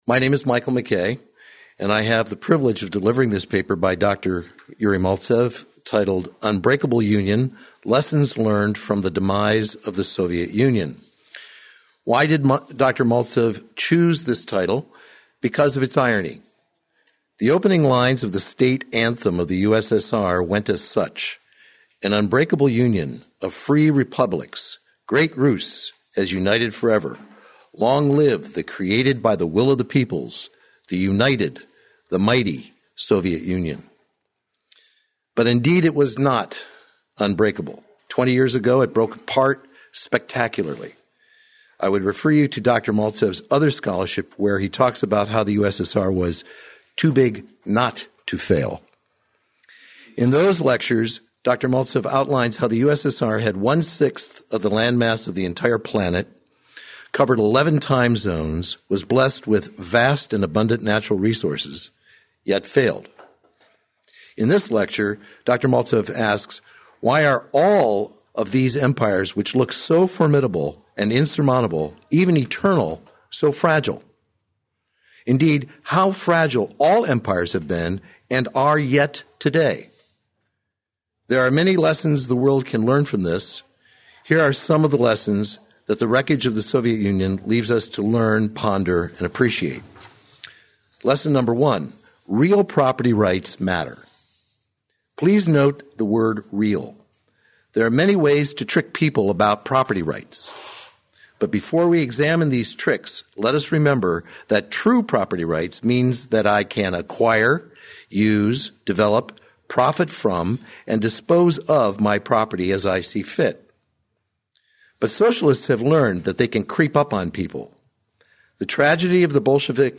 This audio presentation of a paper